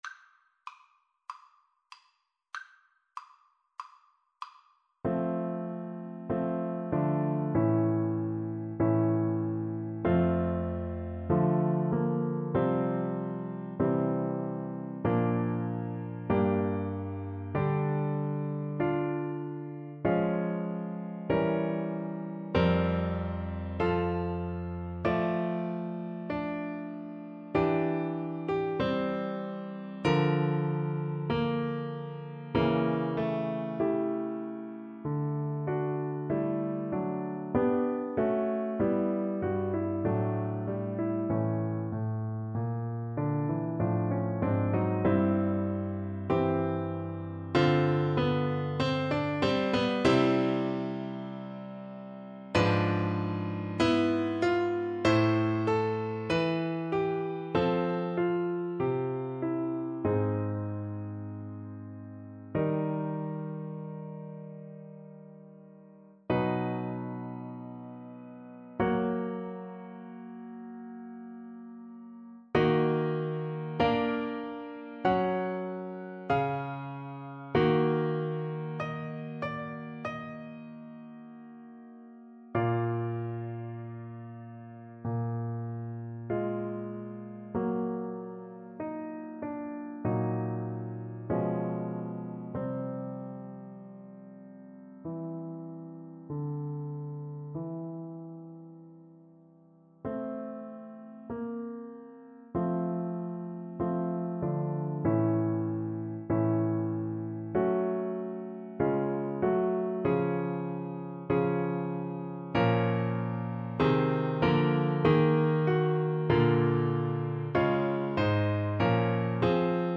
~ = 96 Alla breve. Weihevoll.
Classical (View more Classical Trombone Music)